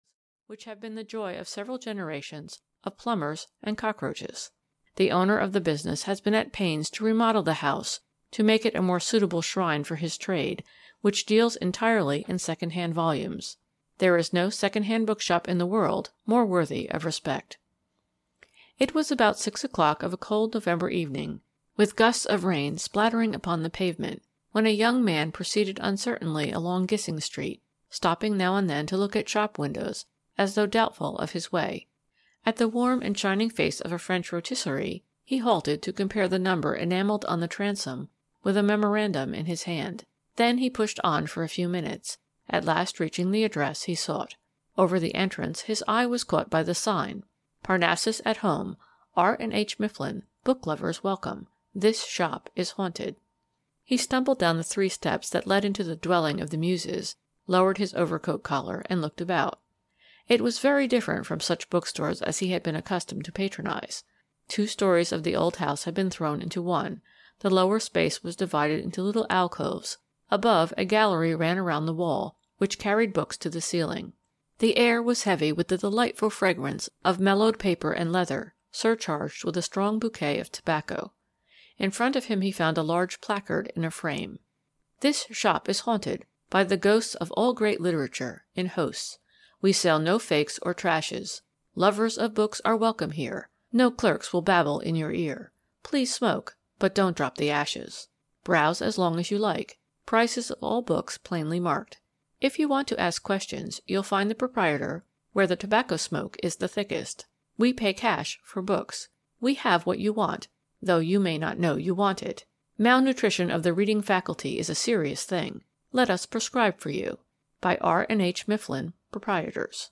The Haunted Bookshop (EN) audiokniha
Ukázka z knihy